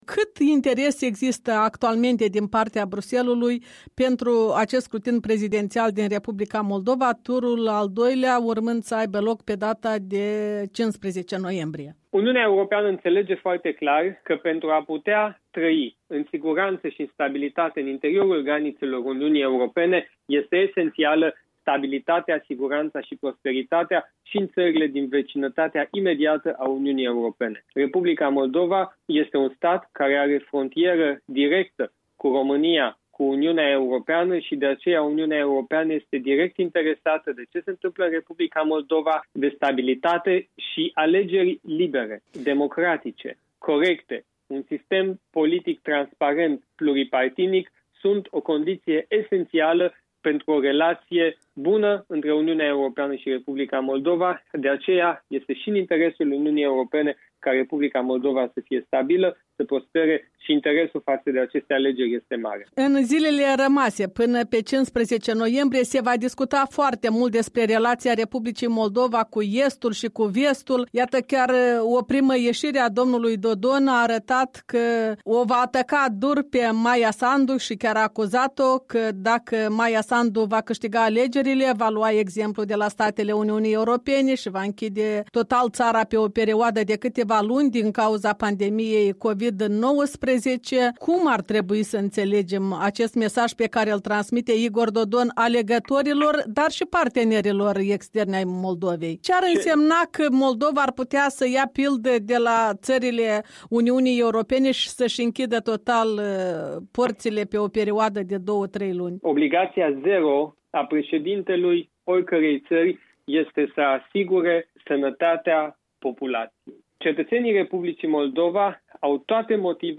Interviu cu Siegfried Mureșan